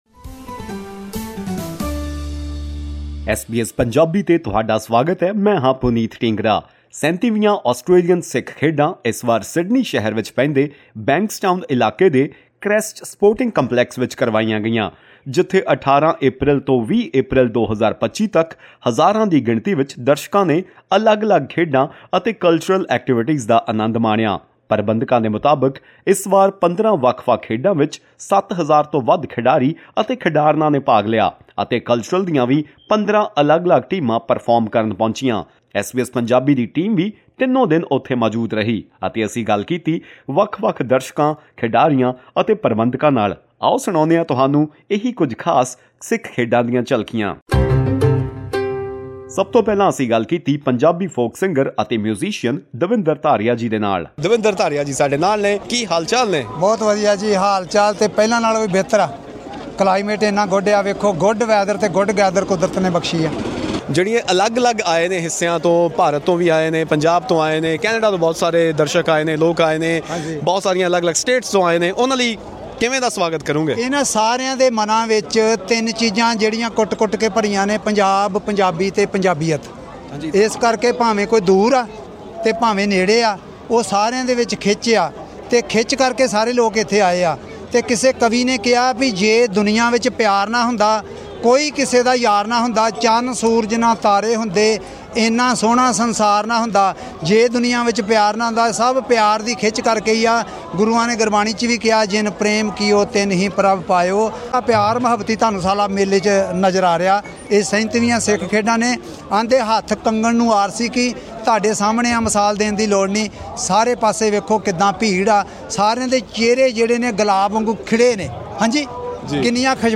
ਸੁਣੋ ਇਹ ਪੂਰੀ ਗੱਲਬਾਤ ਅਤੇ ਗੀਤ ਇਸ ਪੌਡਕਾਸਟ ਵਿੱਚ...
Various local artists at SBS stall at Australian Sikh Games, Sydney.